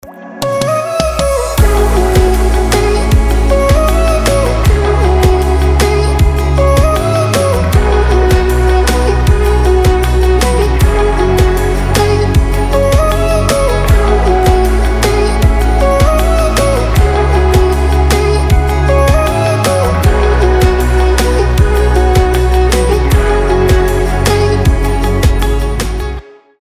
• Качество: 320, Stereo
красивые
мелодичные
без слов